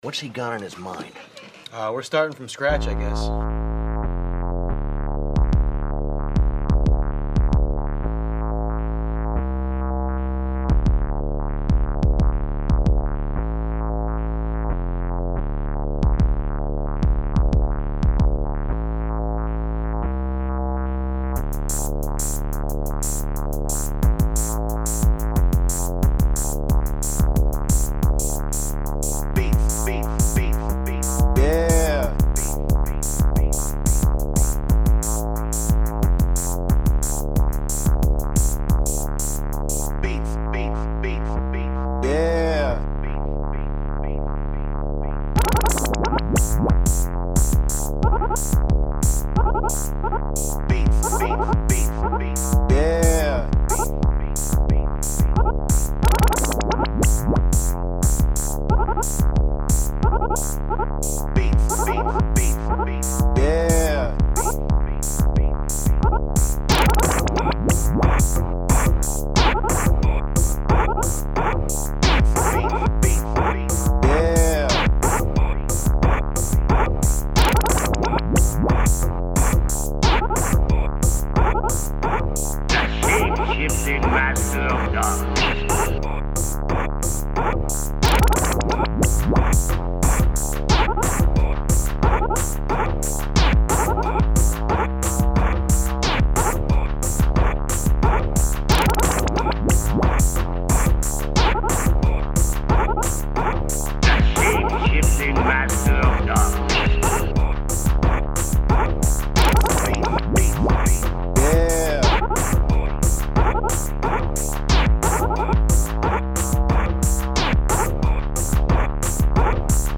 This is normal for electronica, but if you had asked me several years ago if I'd be producing 8-minute tracks without breaking a sweat, I would have laughed sardonically and freaked you out a little with my cynical response. In the end, the remix evolved to become something more akin to two songs being played back to back.